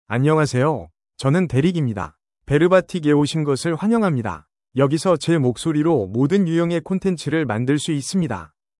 DerekMale Korean AI voice
Derek is a male AI voice for Korean (Korea).
Voice sample
Listen to Derek's male Korean voice.
Male
Derek delivers clear pronunciation with authentic Korea Korean intonation, making your content sound professionally produced.